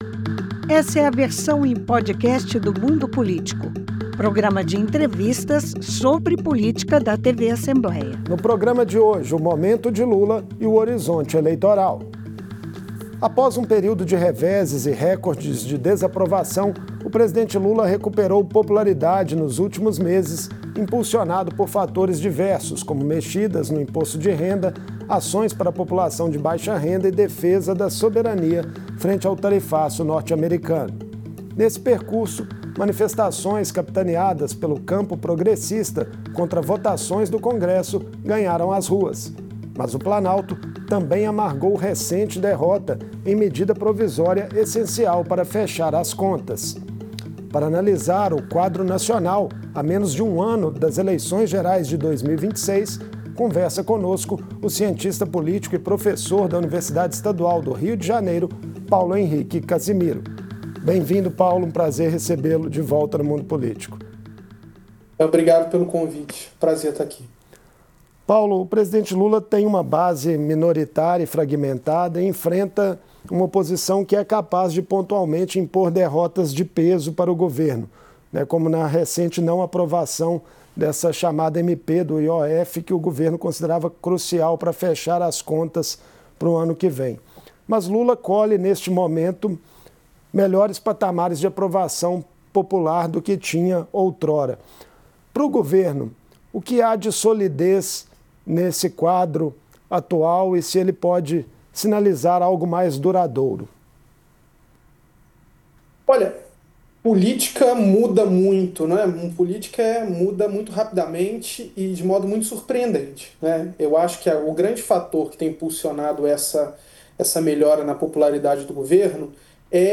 Nos últimos meses, o presidente Lula recuperou popularidade impulsionado por medidas como isenção de imposto de renda, ações para população de baixa renda e a melhora da economia e a defesa da soberania. Por outro lado, o Congresso impôs derrota recente ao Planalto em medida provisória essencial para fechar as contas. Em entrevista